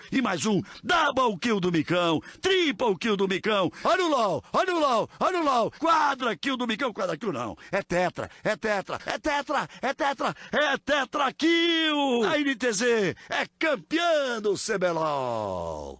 Play, download and share Galvão narrando LOLzinho original sound button!!!!
galvao-narrando-lol.mp3